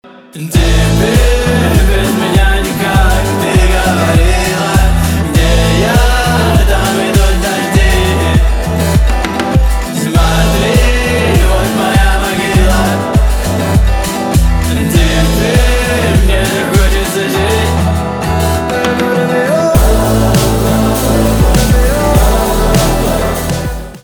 альтернатива
гитара , барабаны , грустные , печальные
депрессивные